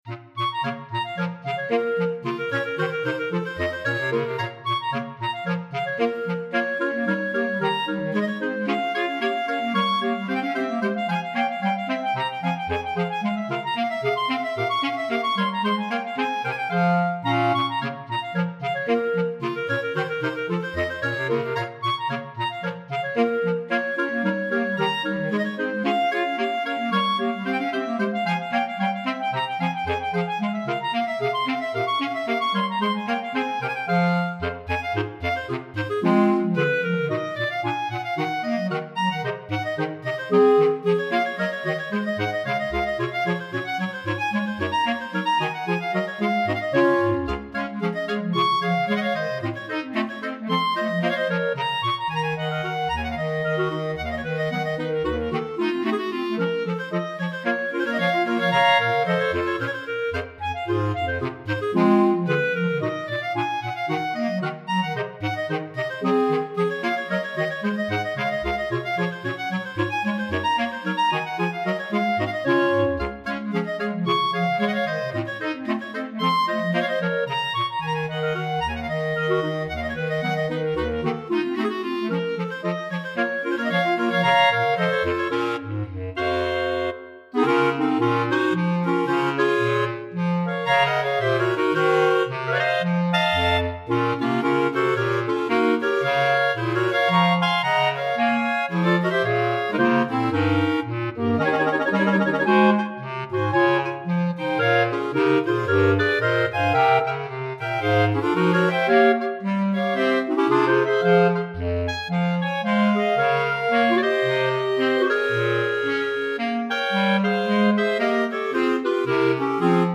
3 Clarinettes en Sib et Clarinette Basse